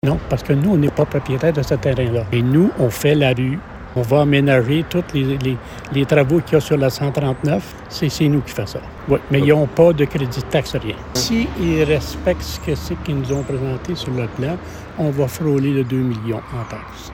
Concernant le dossier de développement sur le site de l’Érablière La Grillade avec notamment l’arrivée de Costco, l’administration municipale s’attend à recevoir d’importants revenus comme l’explique le premier magistrat, Marcel Gaudreau :